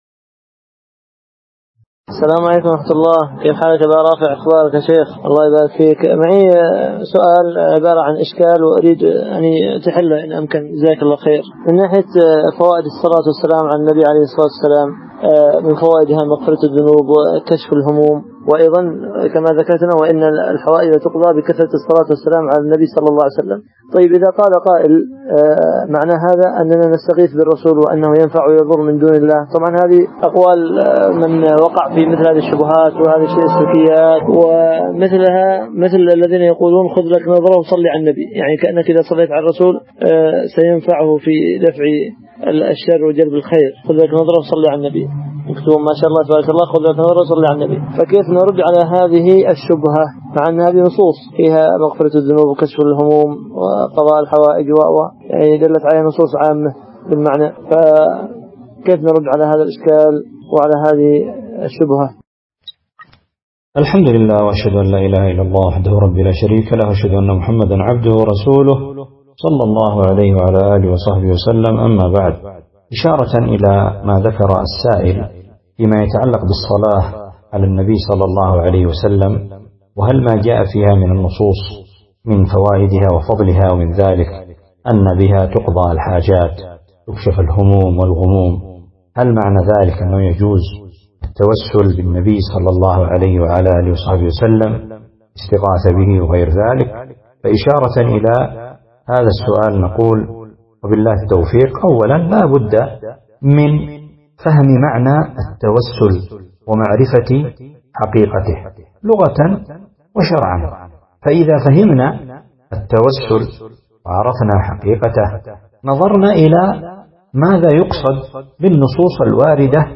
التوسل بين المشروع والممنوع محاضرة ← شيخنا المبارك